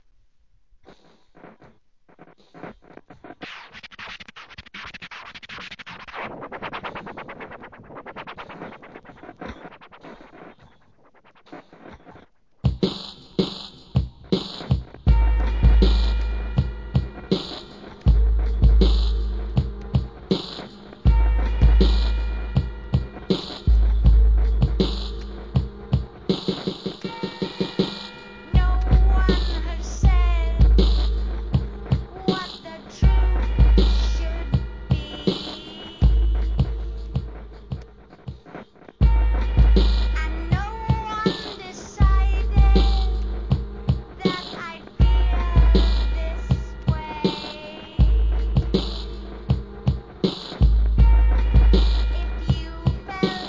HIP HOP/R&B
重たいBEATにスクラッチと言ったヒップホップなテイストで独特の世界です!